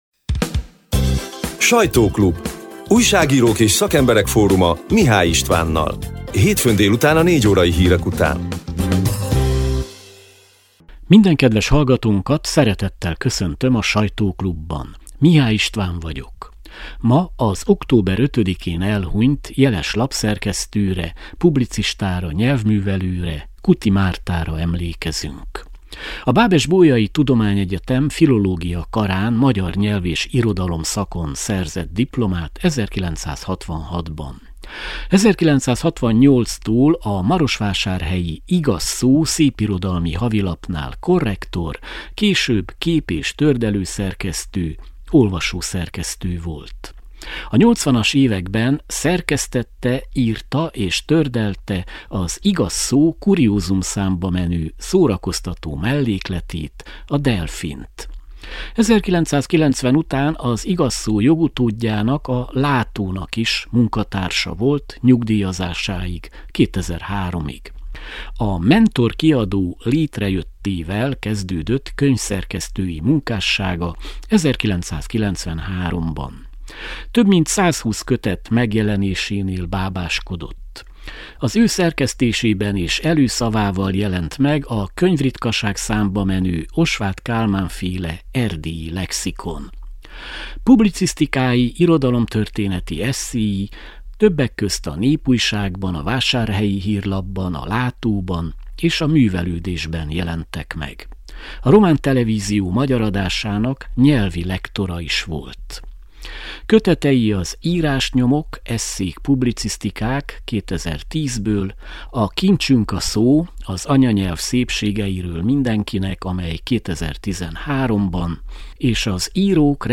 A lejátszóra kattintva az október 13-i, hétfő délutáni műsor kissé rövidített változatát hallgathatják meg.